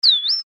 Animals Bird Brown Headed Cowbird Chirp.mp3
animals-bird-brown-headed-cowbird-chirp.mp3